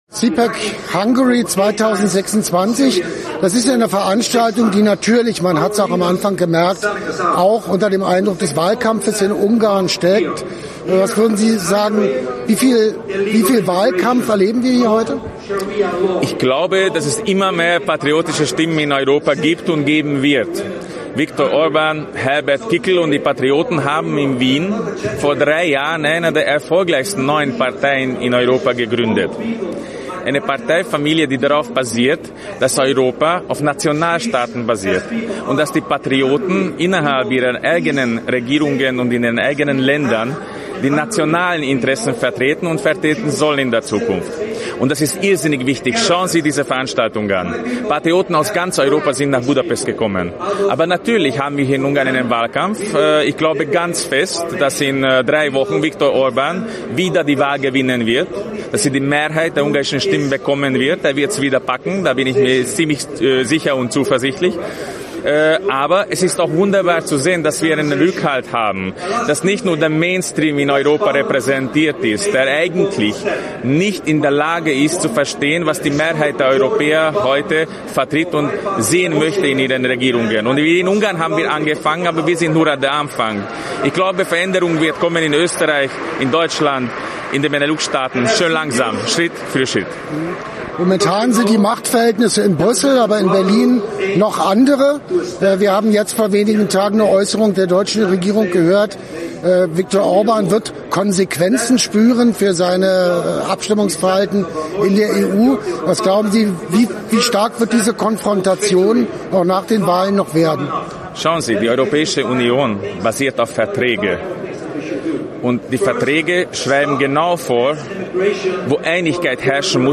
Das Gespräch fand auf
der CPAC Hungary in Budapest statt.